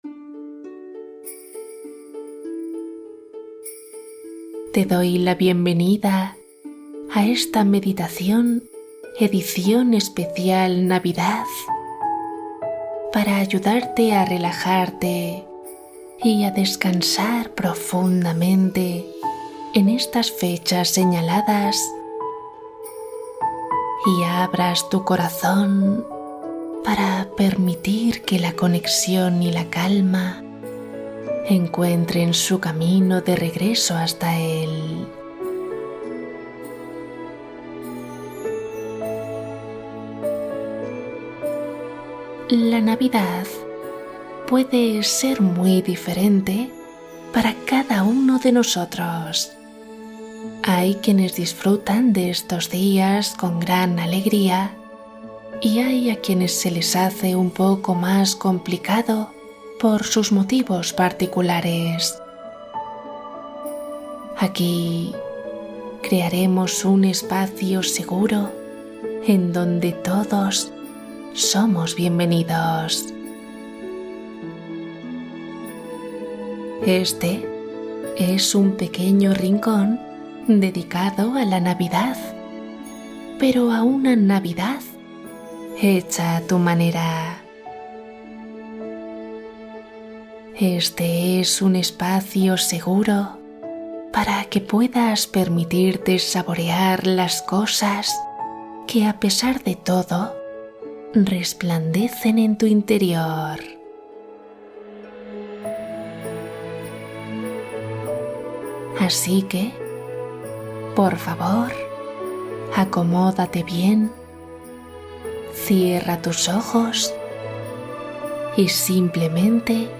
Cuento de Navidad Meditación para dormir profundamente y relajarte